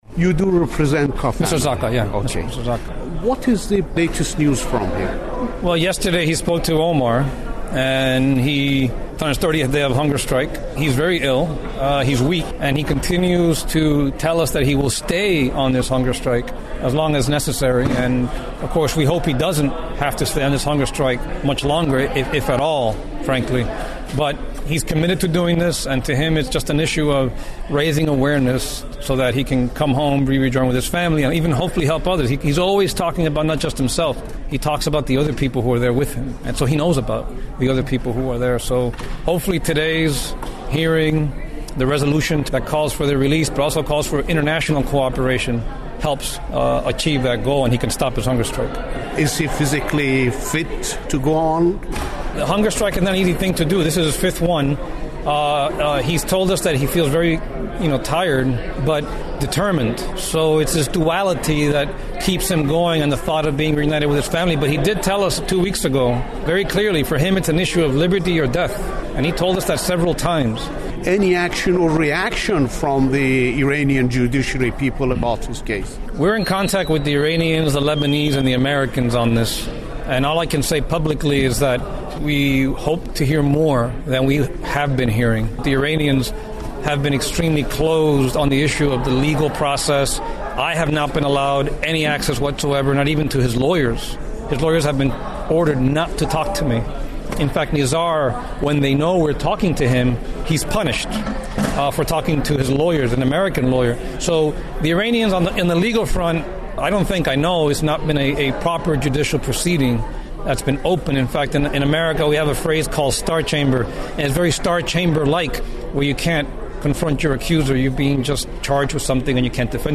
Radio Farda interview